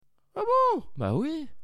Tags: Cosy Corner Bruits de Bouches rires